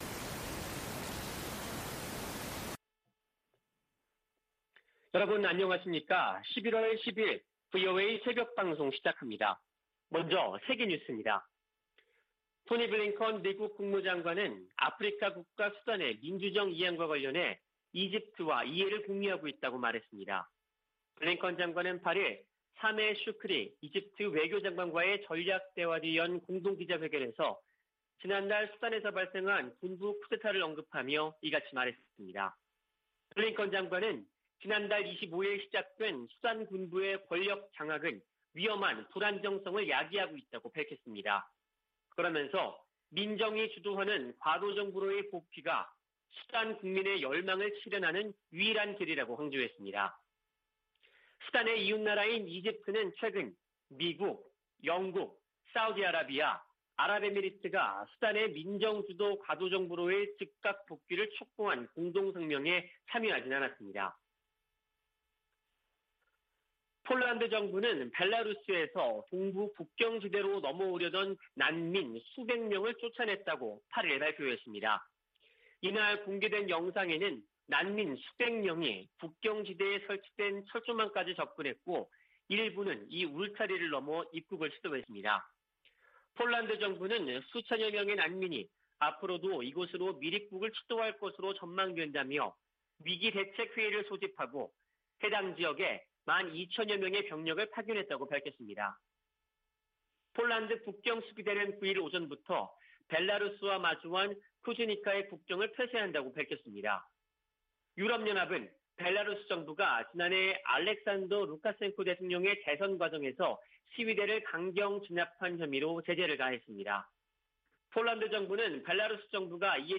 VOA 한국어 '출발 뉴스 쇼', 2021년 11월 10일 방송입니다. 미국 민주당 상·하원 의원들이 대북 인도적 지원을 위한 규정 완화를 촉구하는 서한을 조 바이든 대통령에게 보냈습니다. 일본은 북 핵 위협 제거되지 않은 상태에서의 종전선언을 우려하고 있을 것으로 미국의 전문가들이 보고 있습니다. 최근 북-중 교역이 크게 증가한 가운데 코로나 사태 이후 처음으로 열차가 통행한 것으로 알려졌습니다.